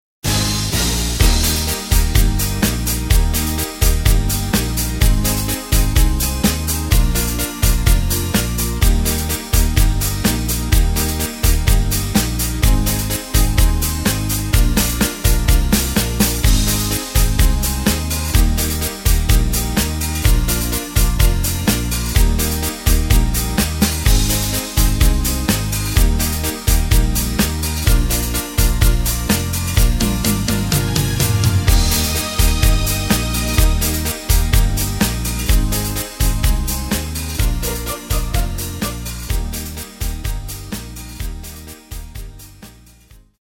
Tempo:         126.00
Tonart:            Eb
Schlager Instrumental - TROMPETE - aus dem Jahr 2012!
Playback mp3 Demo